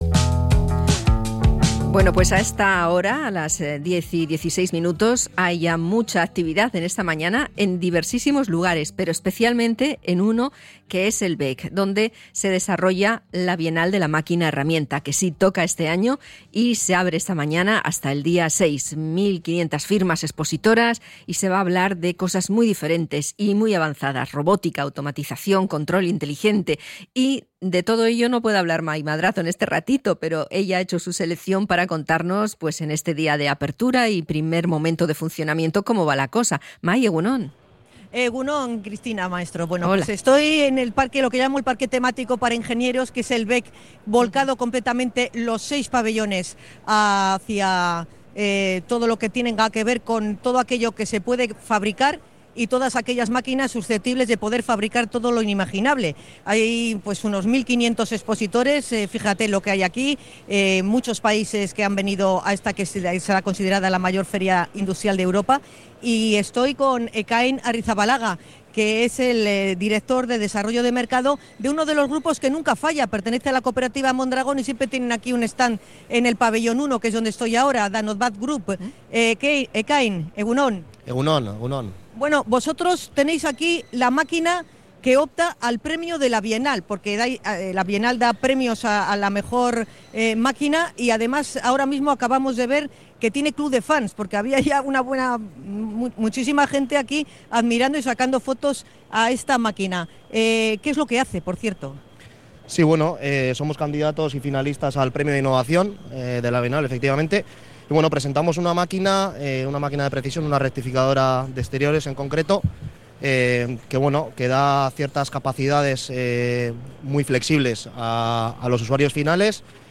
Hablamos con expositores y visitantes de la BIEMH